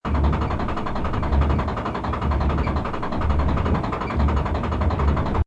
CHQ_FACT_stomper_raise.ogg